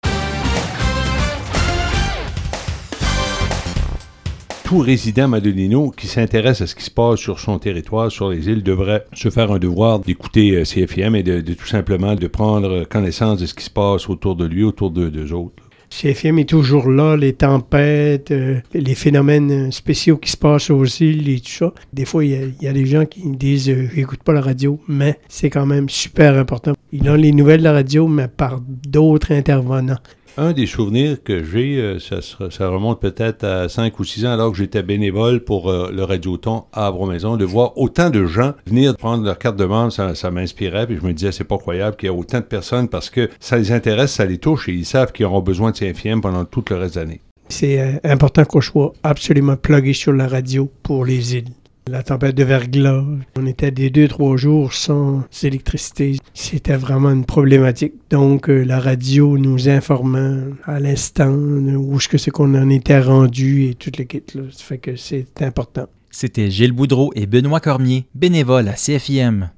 Dans le cadre du 44e Radiothon, les animateurs et animatrices bénévoles du Son de la mer ont été invités à témoigner de leur lien avec la radio communautaire des Îles et de que ça représente pour eux.